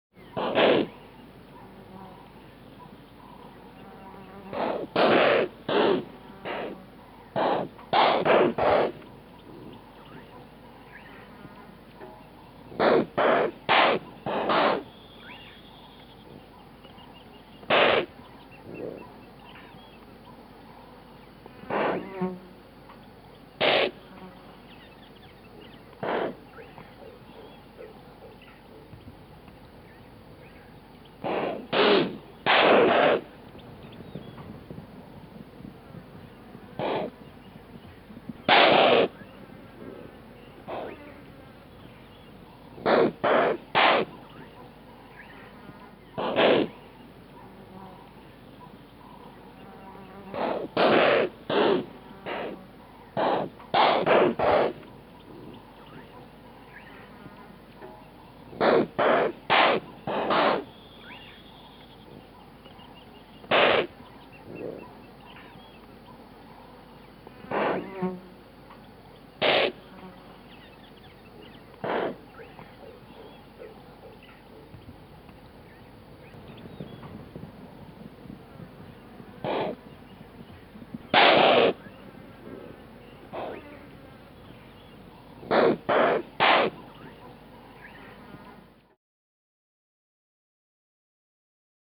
animal
Impala Alarm Call and Explosive Chuffs